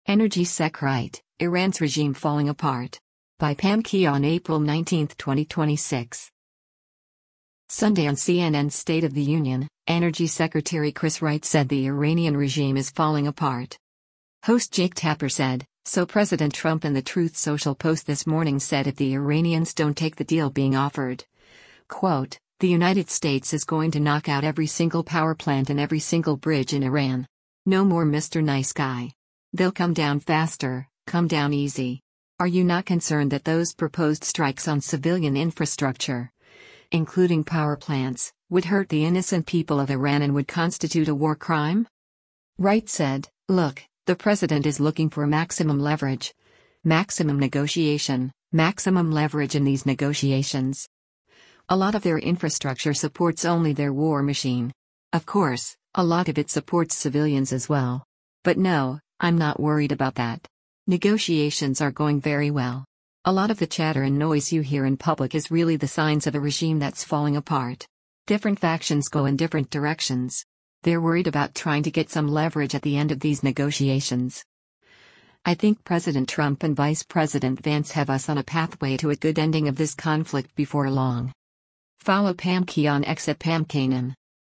Sunday on CNN’s “State of the Union,” Energy Secretary Chris Wright said the Iranian regime is “falling apart.”